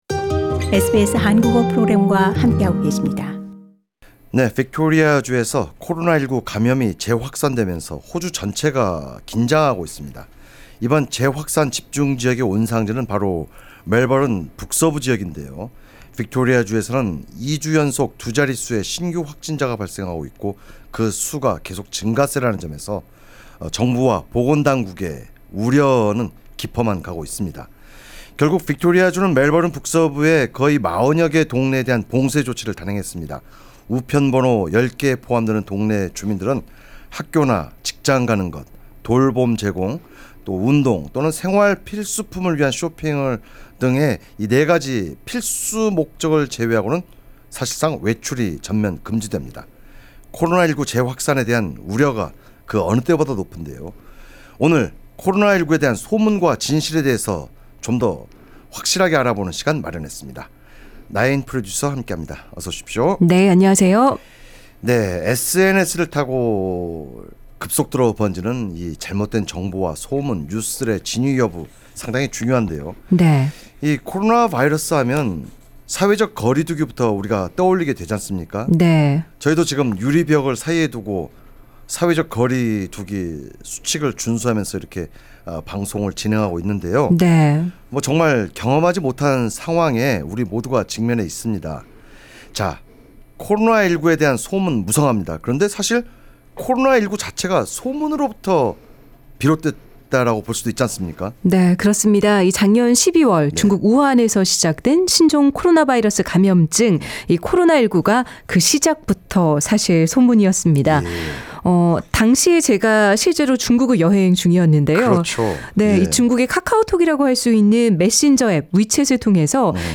저희도 지금 유리 벽을 사이에 두고 사회적 거리두기를 지키면서 이렇게 방송을 진행하고 있는데요.